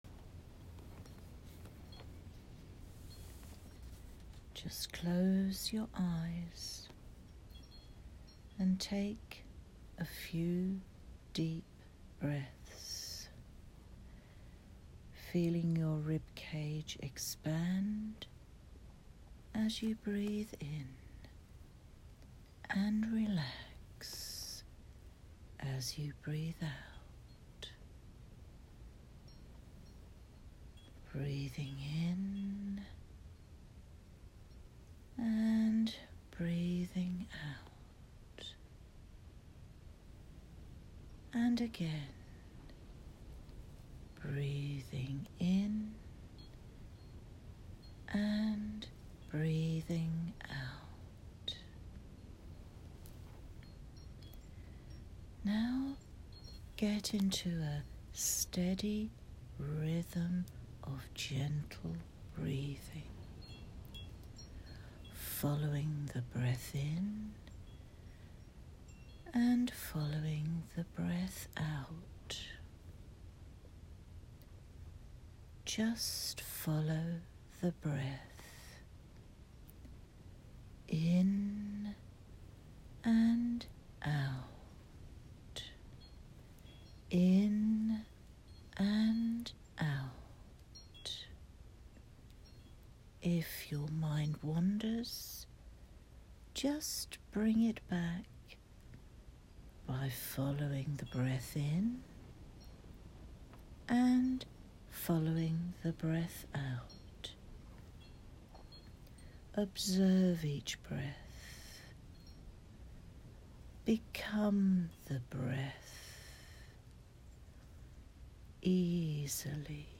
Free_meditation_arvvpv.m4a